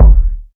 KICK.133.NEPT.wav